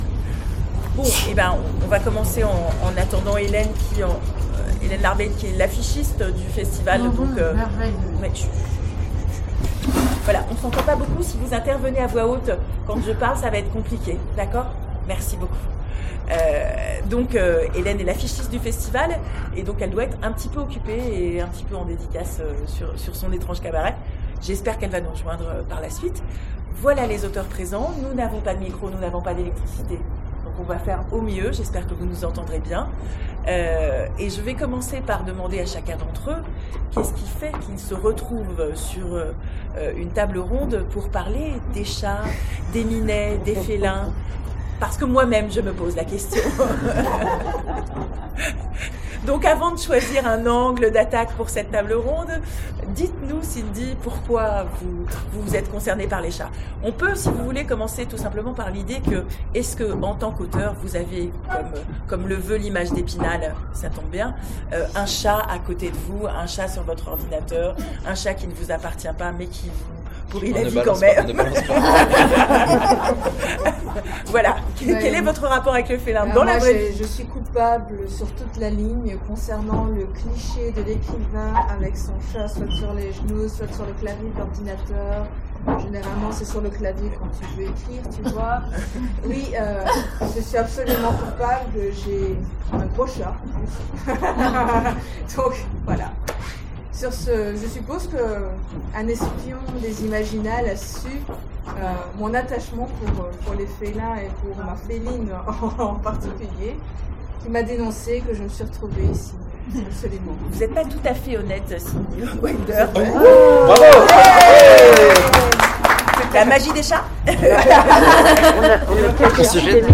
Imaginales 2016 : Conférence Chats gentils, minets louches…
Télécharger le MP3 Note : suite à des coupures de courants, la conférence est amputée d'une dizaine de minutes au milieu de sa captation.